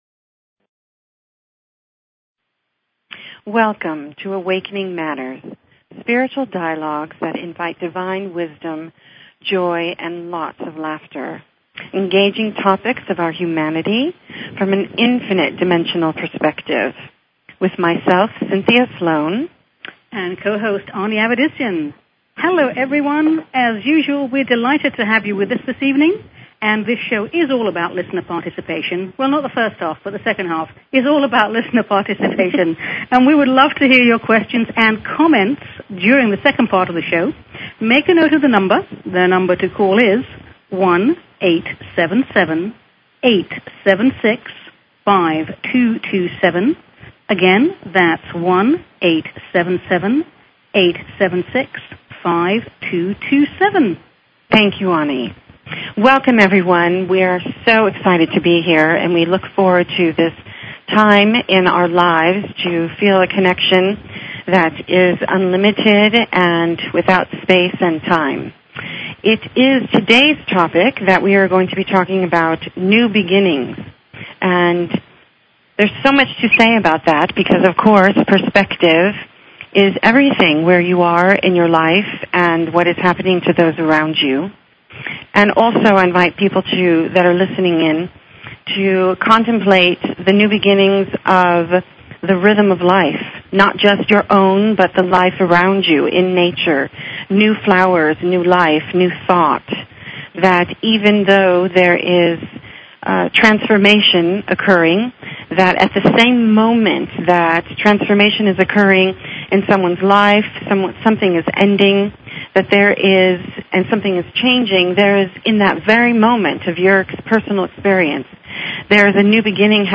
Talk Show Episode, Audio Podcast, Awakening_Matters and Courtesy of BBS Radio on , show guests , about , categorized as
A spiritual dialogue that invites divine wisdom, joy and laughter. Engaging topics of our humanity from an infinite dimensional perspective.